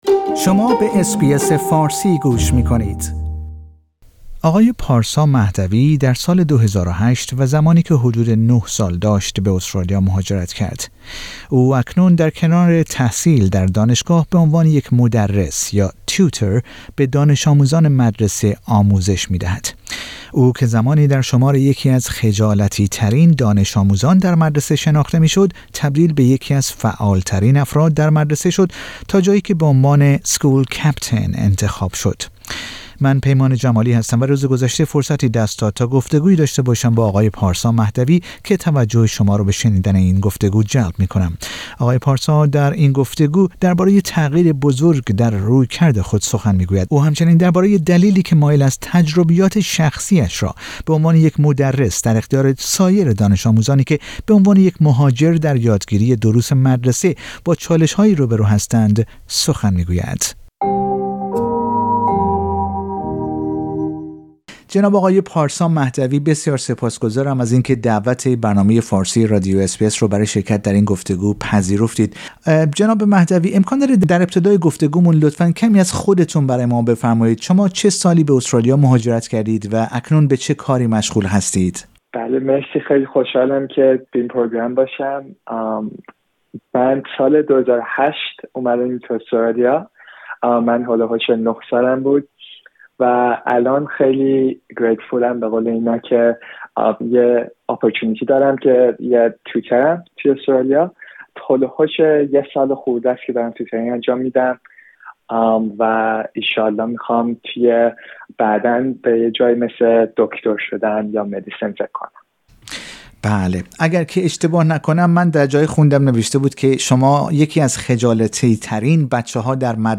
در گفتگو با رادیو اس بی اس فارسی